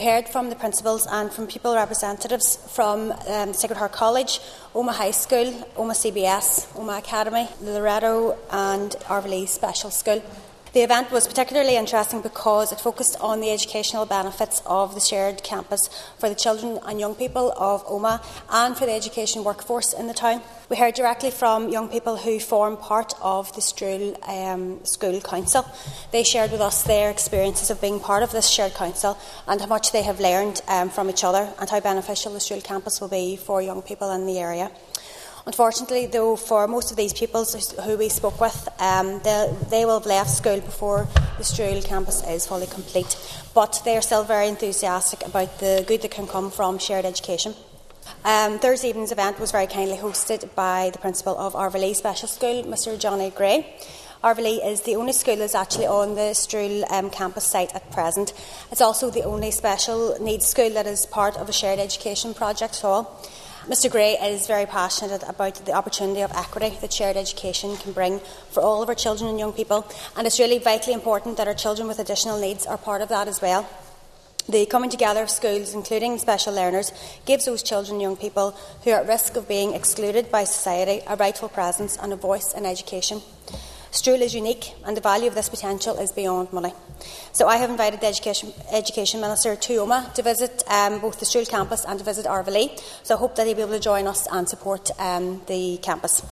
This week at the Assembly in Stormont, West Tyrone MLA Nicola Brogan urged Minister Paul Given to visit the school, saying its inclusion in the Strule project is unique, and both Arvalee and the wider campus project deserve support………….